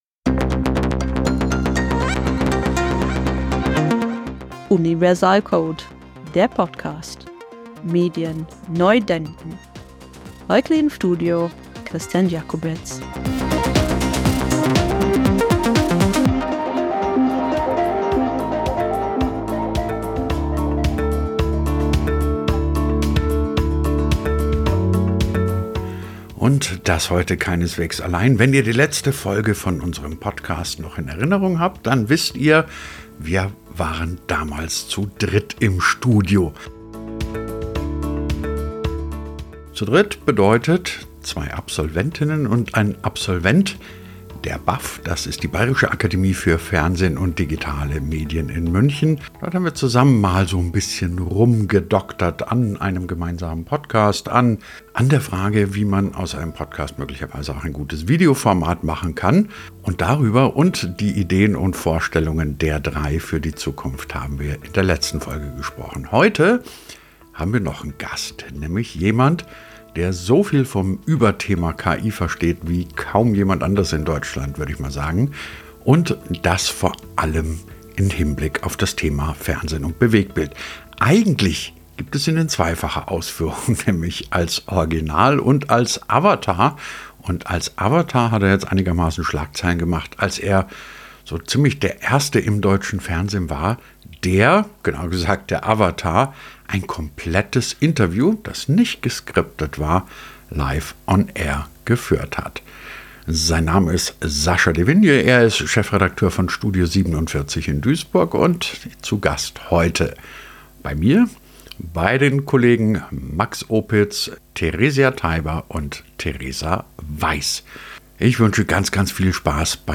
Mit im Studio sind außerdem die BAF-Absolventen